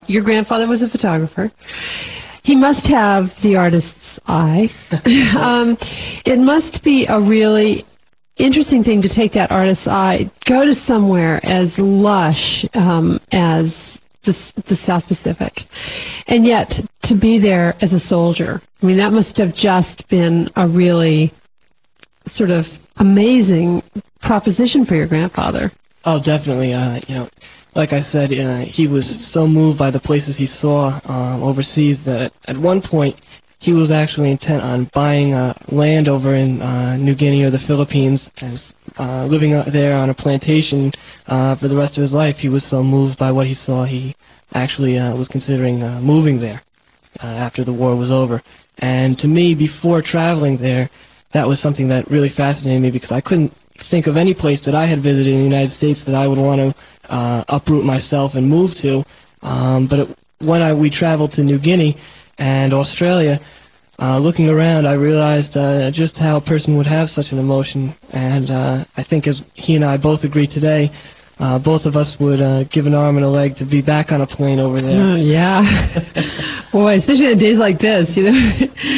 WZKE Radio Interview